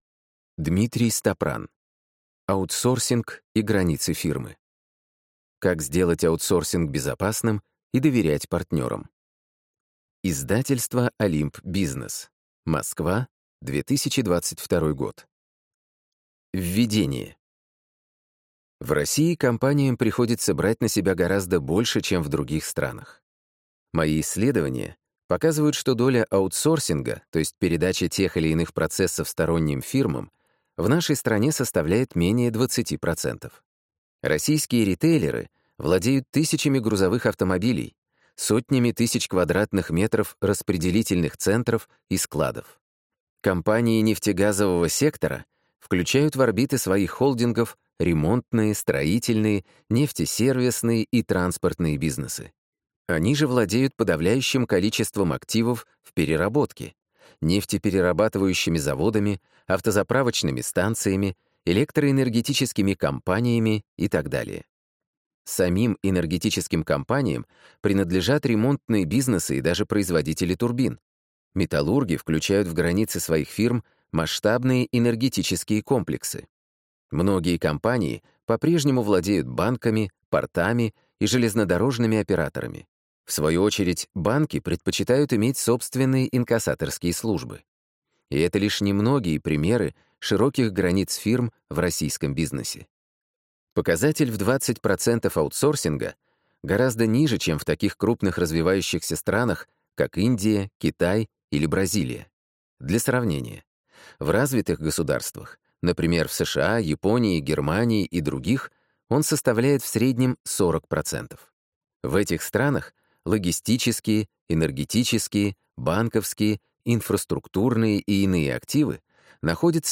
Аудиокнига Аутсорсинг и границы фирмы. Как сделать аутсорсинг безопасным и доверять партнерам | Библиотека аудиокниг